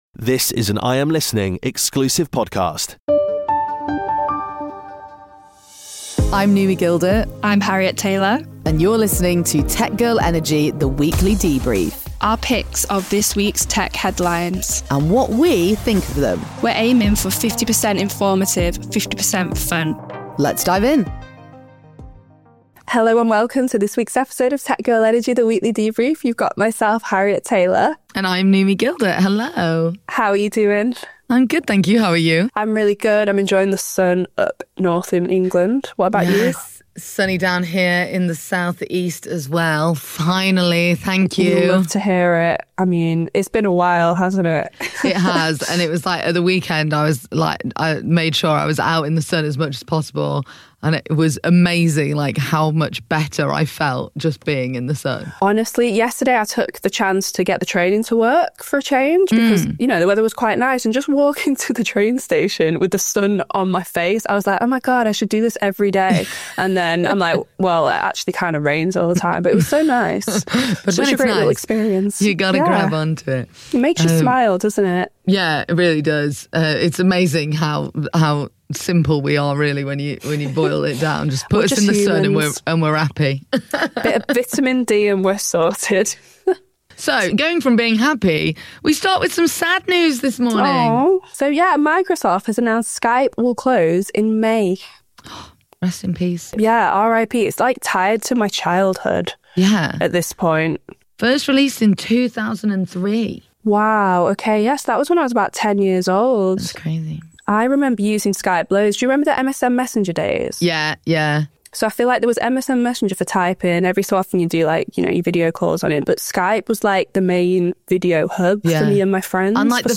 Highlights: Sunny Days in the UK: A light-hearted start with both hosts enjoying rare sunny weather across England. Farewell Skype (RIP): Microsoft announces Skype will close down in May.